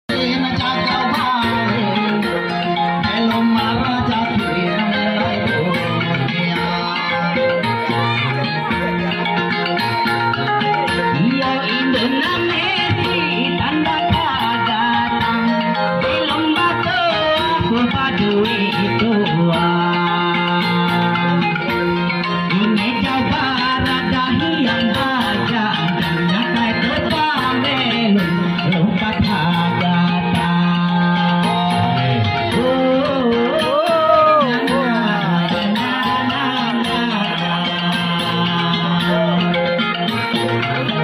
karungut tandak timang acara ritual